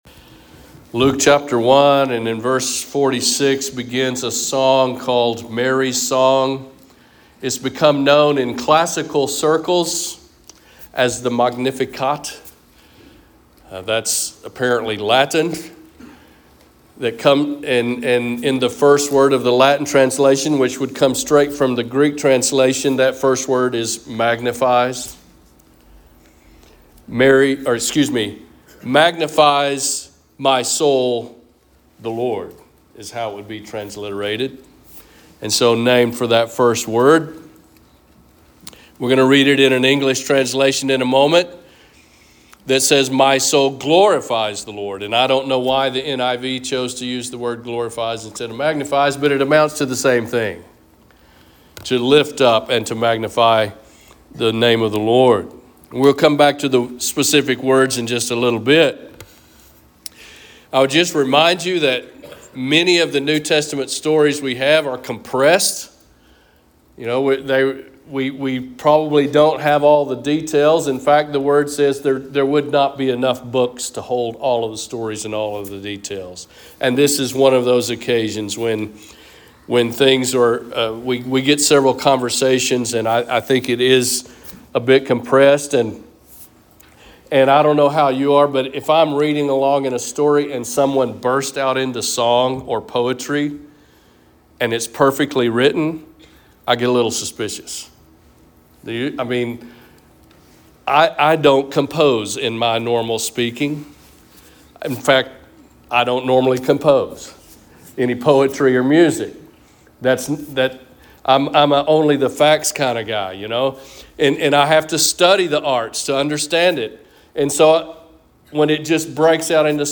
Sermons | Lawn Baptist Church